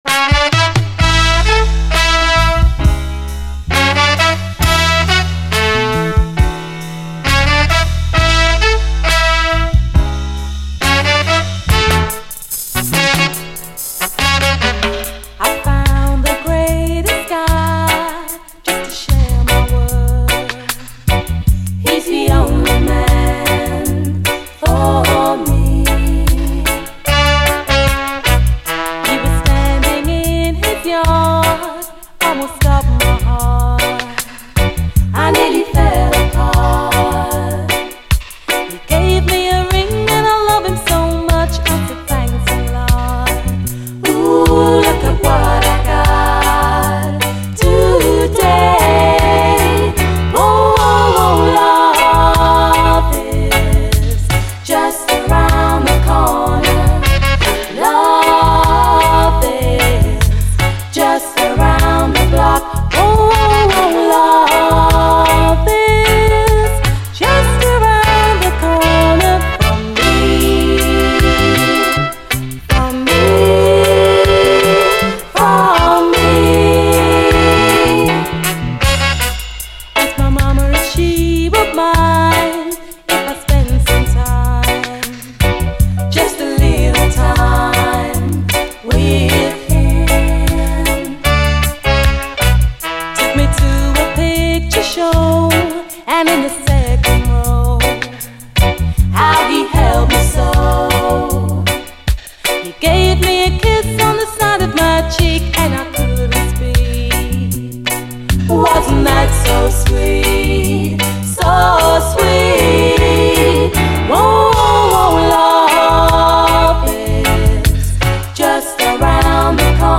ヴォコーダー使いのメロウ・ダンサー